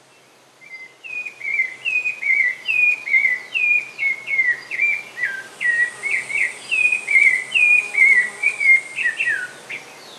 SABIÁ-LARANJEIRA
Nome em Inglês: Rufous-bellied Thrush
É o mais conhecida entre as sabiás, sendo identificada pela cor ferrugínea do ventre e seu canto persistente durante o período reprodutivo. Em geral, a partir do mês de setembro, mesmo antes do amanhecer, inicia sua vocalização característica para atrair a fêmea e demarcar seu território.